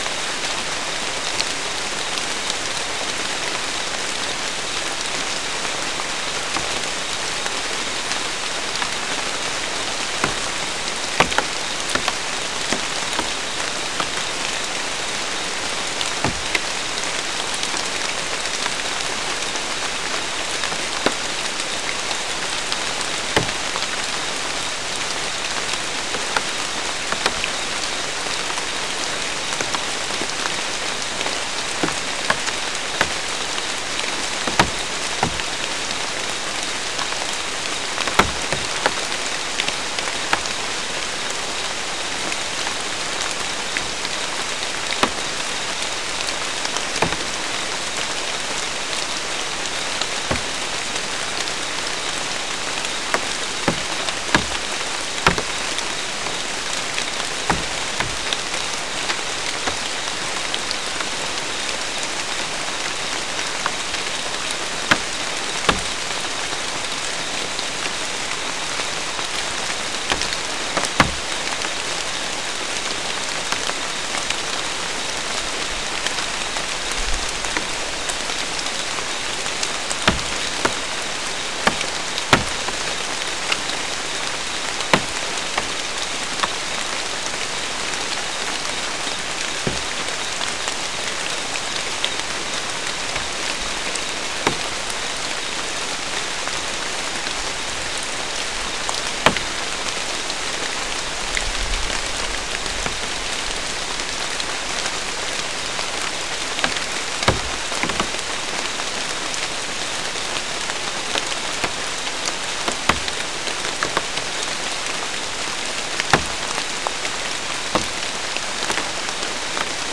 Non-specimen recording: Soundscape Recording Location: South America: Guyana: Sandstone: 2
Recorder: SM3